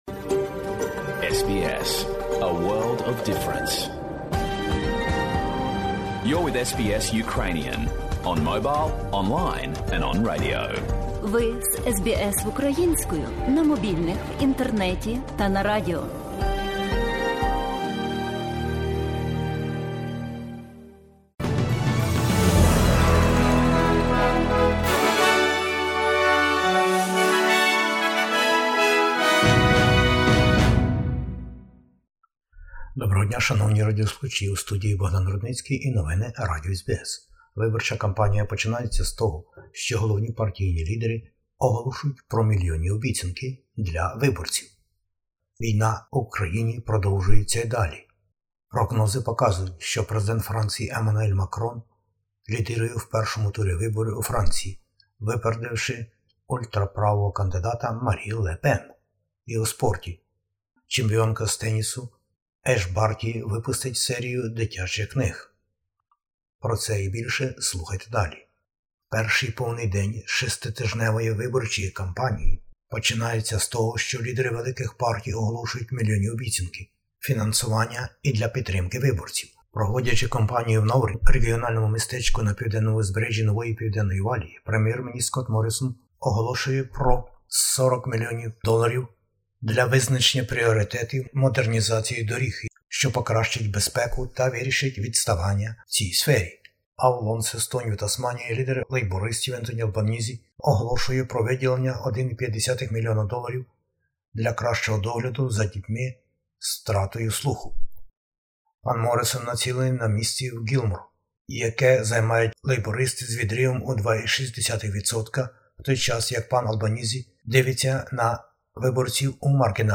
Бюлетень новин SBS українською. Федеральні вибори - 21 травня, а передвиборча аґітація уже почалася.